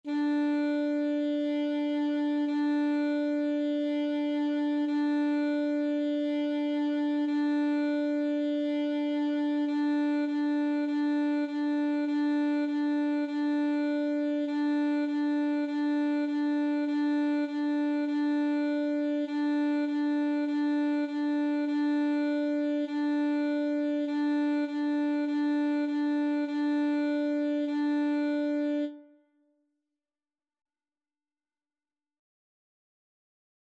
Free Sheet music for Alto Saxophone
D5-D5
4/4 (View more 4/4 Music)
Saxophone  (View more Beginners Saxophone Music)
Classical (View more Classical Saxophone Music)